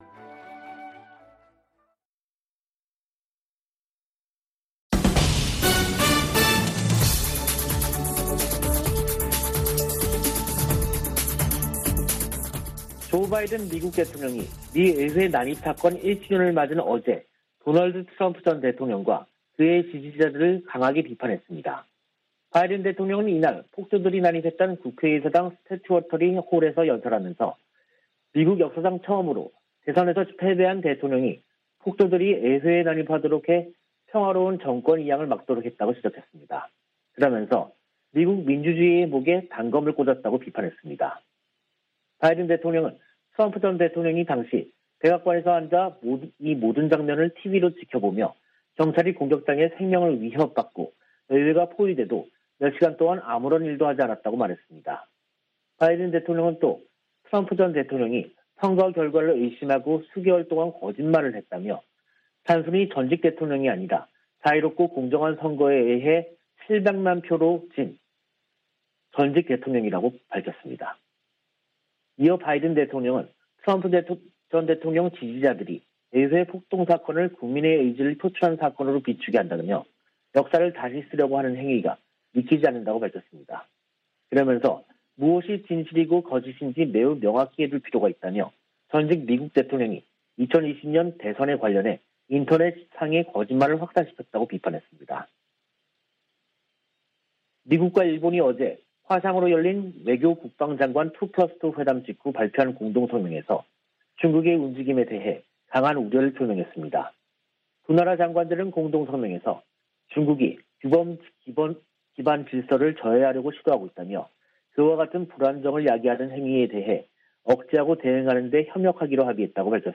VOA 한국어 간판 뉴스 프로그램 '뉴스 투데이', 2022년 1월 7일 3부 방송입니다. 미국과 일본은 북한의 핵과 미사일 개발에 강한 우려를 표하고 유엔 안보리 결의 준수를 촉구했습니다. 미 국방부는 북한의 미사일 시험발사에 관해 구체적 성격을 평가하고 있다며, 어떤 새로운 능력도 심각하게 받아들인다고 밝혔습니다. 미 국무부는 북한 탄도미사일 관련 안보리 소집 여부에 대해, 동맹과 함께 후속 조치를 논의 중이라고 밝혔습니다.